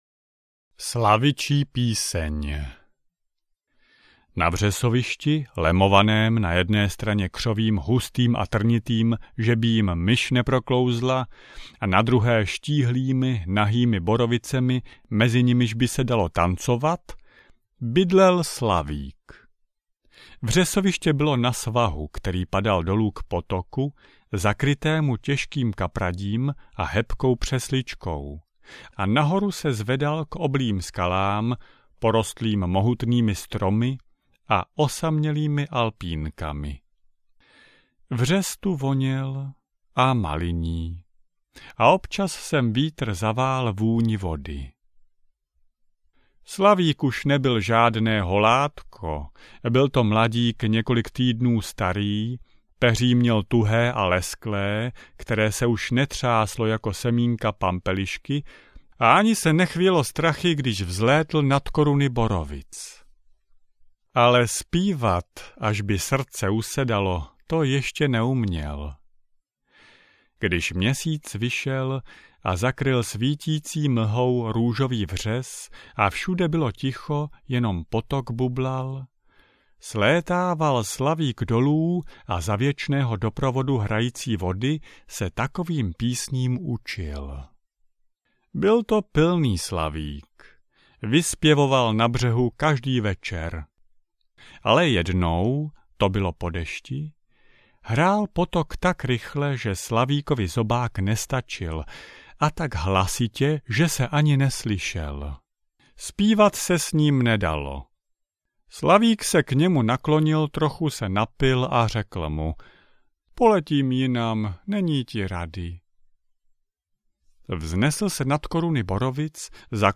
Kapky rosy audiokniha
Ukázka z knihy